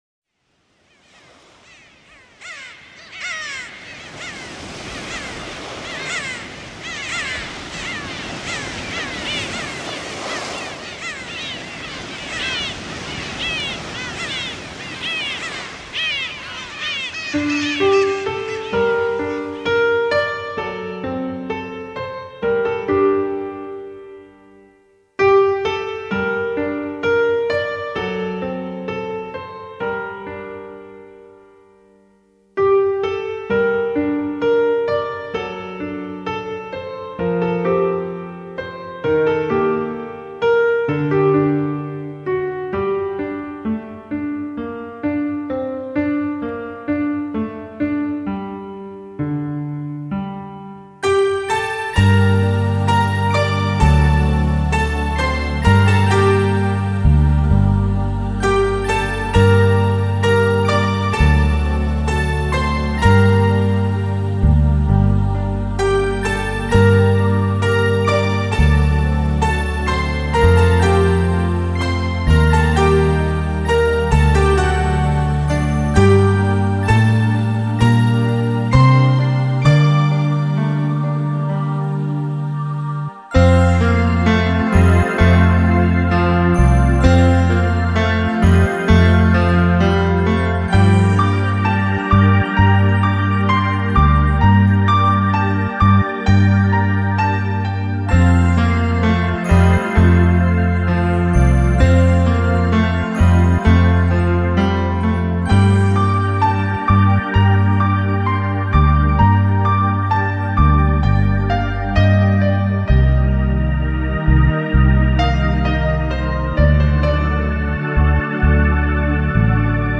演唱歌手：新世纪音乐